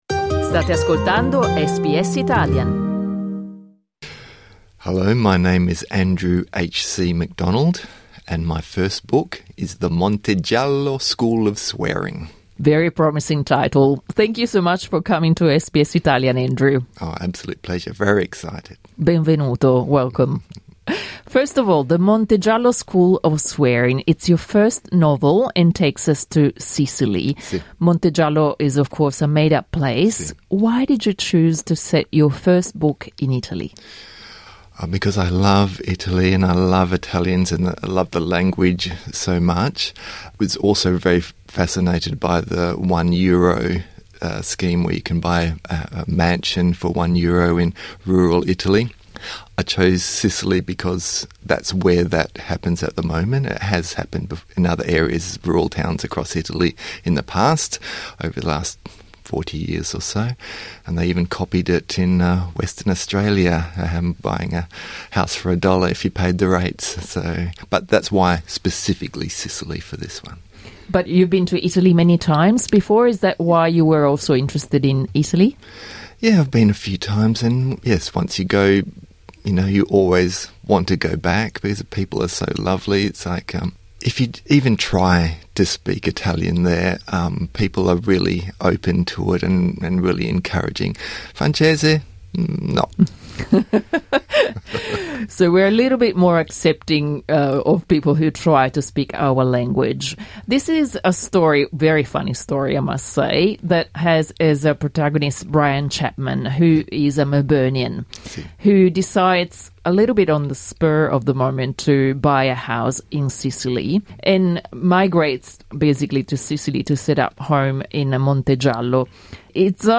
Clicca sul tasto "play" in alto per ascoltare l'intervista in inglese La vendita di case ad un euro è iniziata da qualche tempo in alcuni comuni italiani , con l'obiettivo di attrarre investitori in paesini che si sono, negli anni, spopolati.